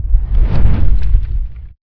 fireball_03.WAV